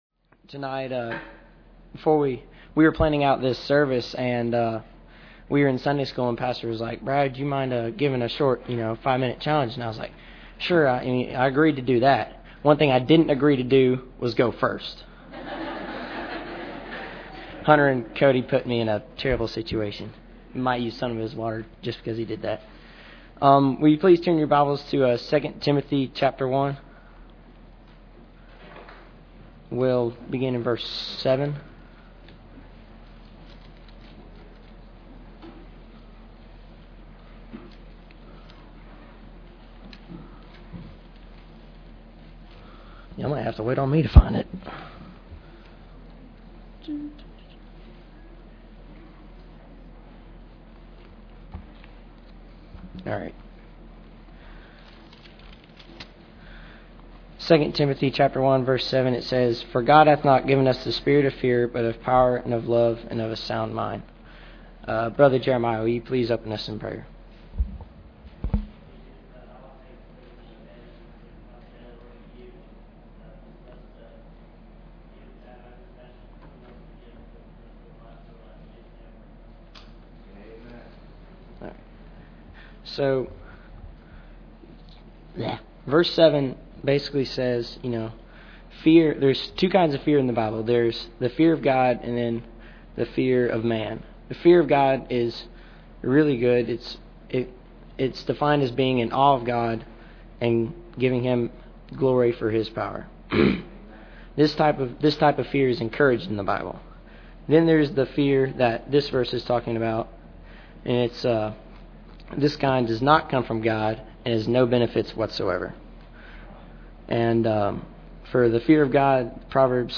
Members of the Youth Group and College/Career Class lead the service.
Service Type: Sunday Evening